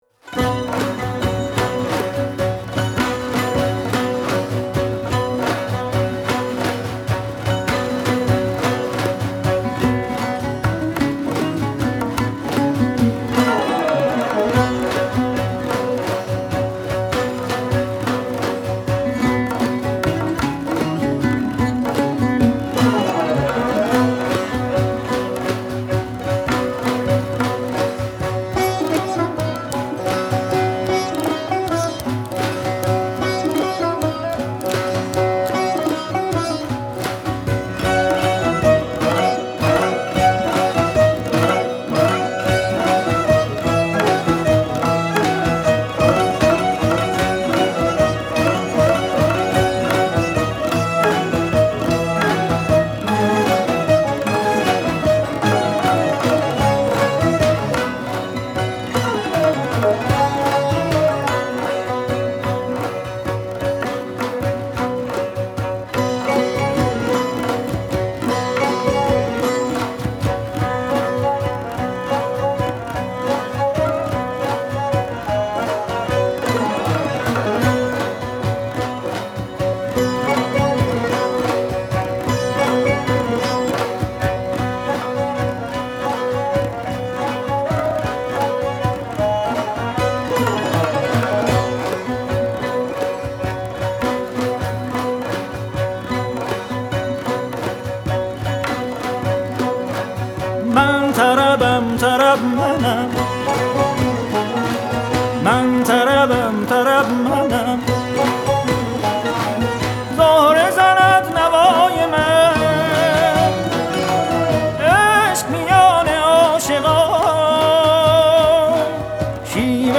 دسته : موسیقی ملل